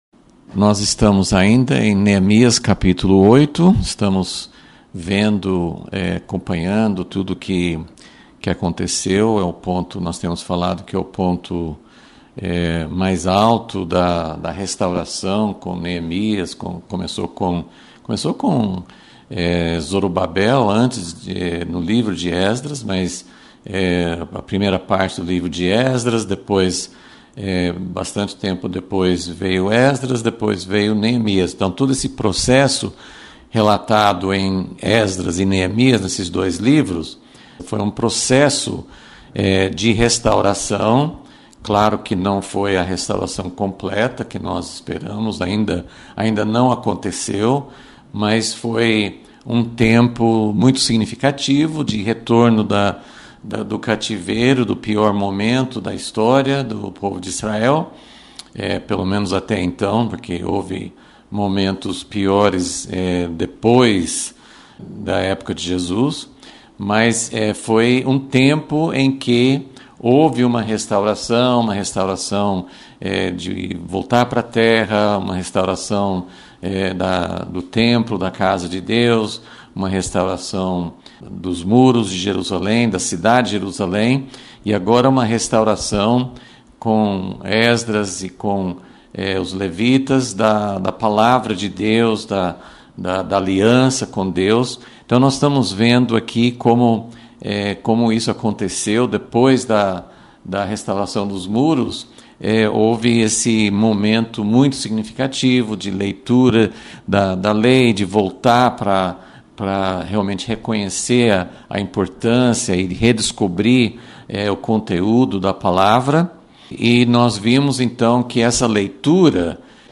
Aula 22 – Vol.36 – A festa dos Tabernáculos com alegria